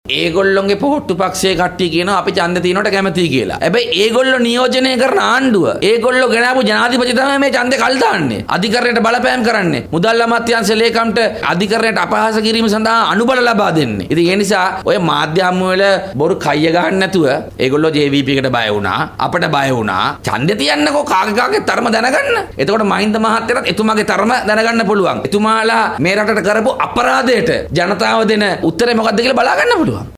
ඔහු මේ බව කියා සිටියේ ඊයේ පැවති මාධ්‍ය සාකාච්චාවක අදහස් පල කරමින් .